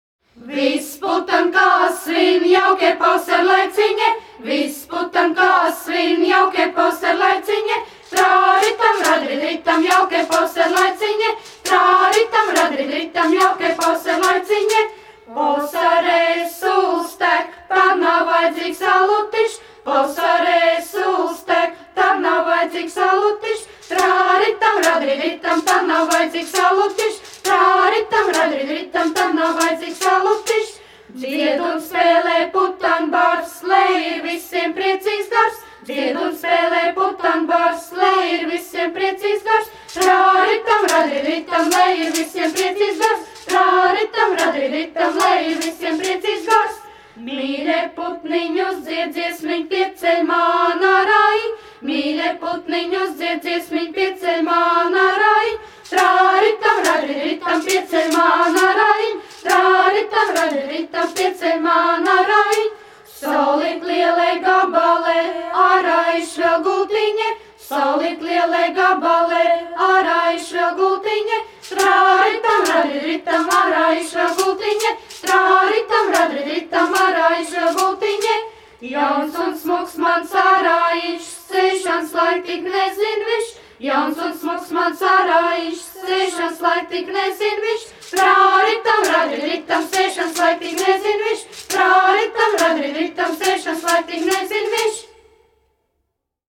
Zūriņ - folkoras kopa, izpildītājs
Tautas mūzika
Dziesmas
Latvijas Radio